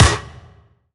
SNARE 093.wav